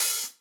TM88 OldOpen-Hat.wav